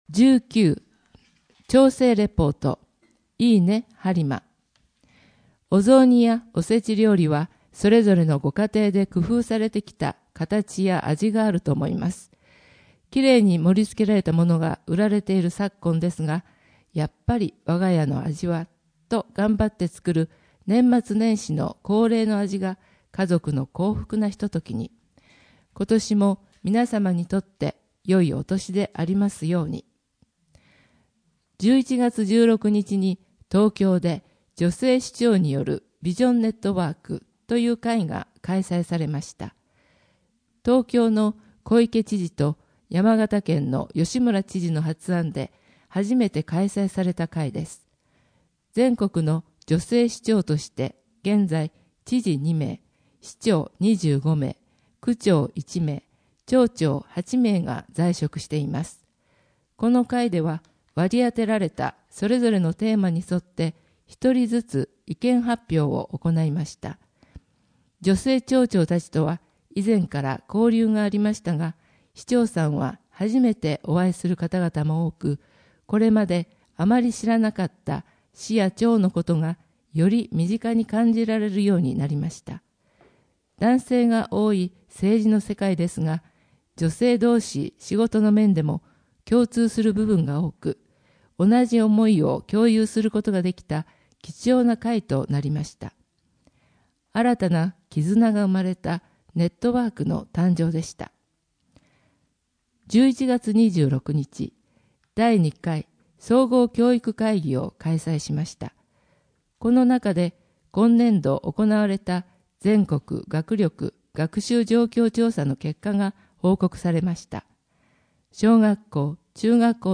声の「広報はりま」はボランティアグループ「のぎく」のご協力により作成されています。